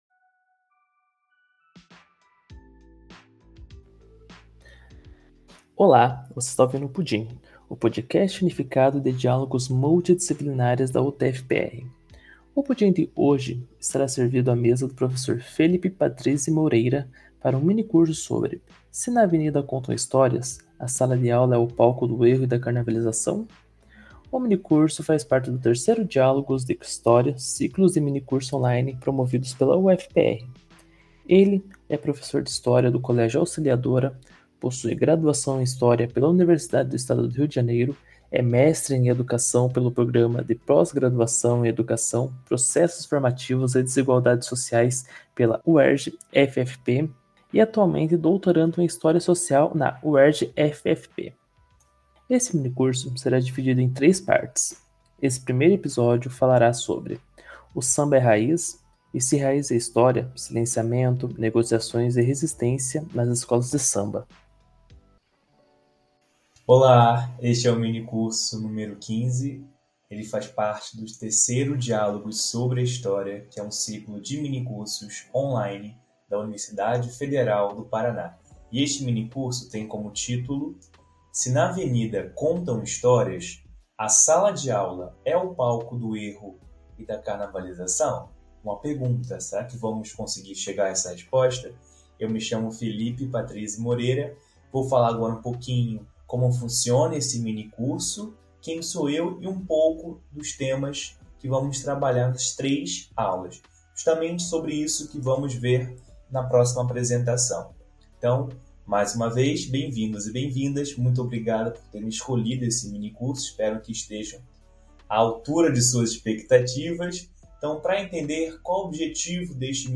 a sala de aula é o palco do erro e da carnavalização?” em um minicurso dividido em três partes. Neste primeiro episódio veremos um panorama geral de como acontecem os desfiles das escolas de samba. De onde surgiram, quem vai acolher o termo “escola de samba”, os primeiros concursos, os problemas e por onde desfilaram.